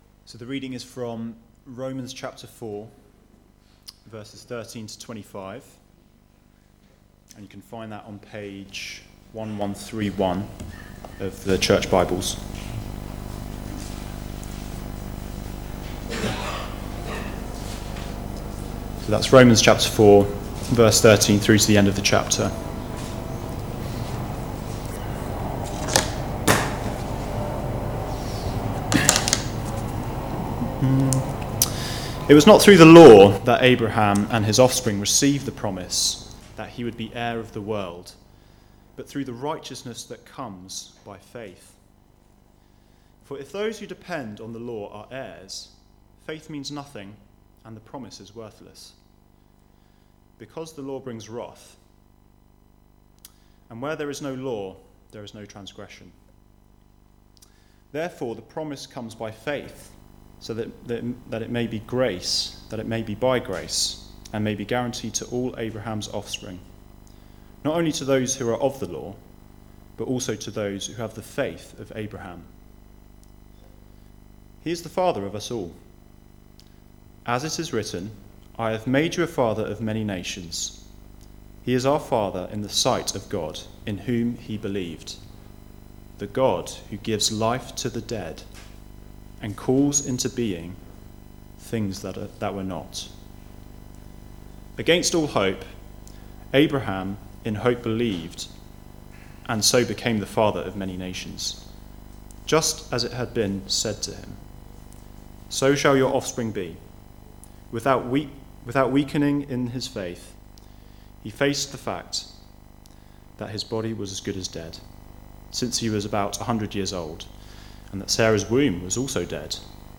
The Riches of Grace Passage: Romans 4:13-25 Service Type: Weekly Service at 4pm « Blessed through Faith Peace with God